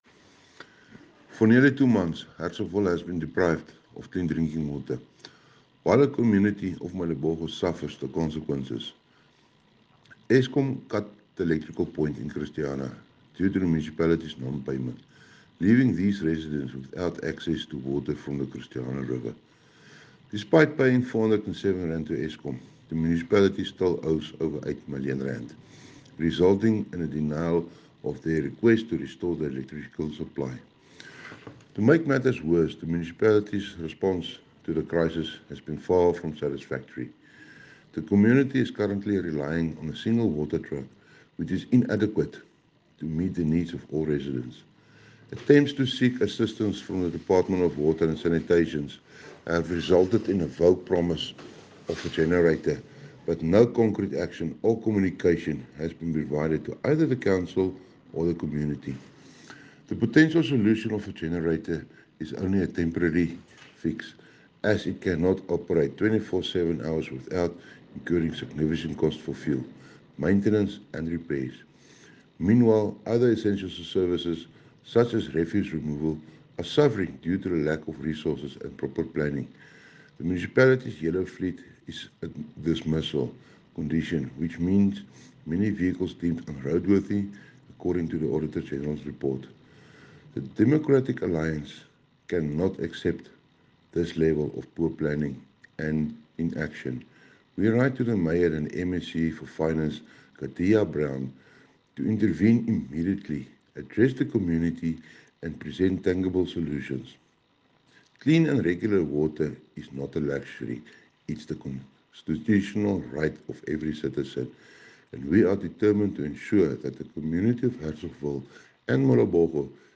Afrikaans soundbites by Cllr Johann Steenkamp and